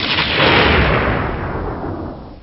Heavy Thunder Sound Effect Free Download
Heavy Thunder